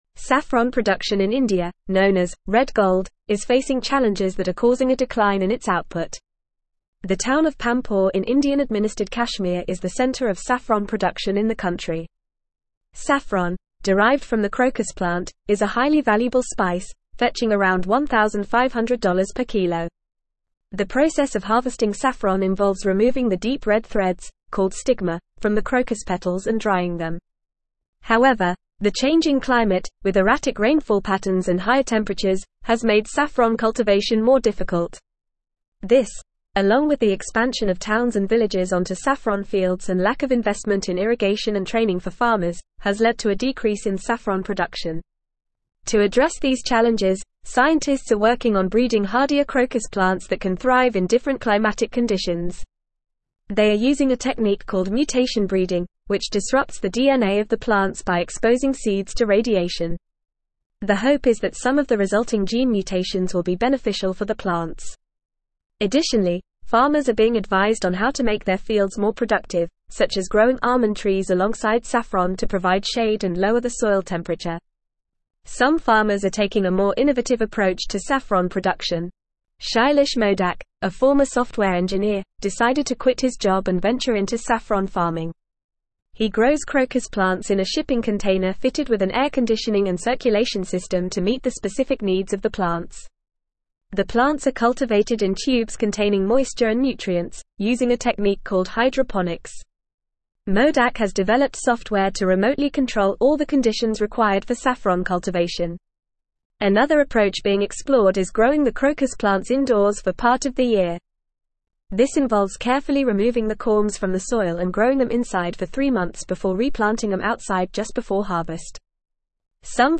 Fast
English-Newsroom-Advanced-FAST-Reading-Saffron-production-in-India-challenges-and-solutions.mp3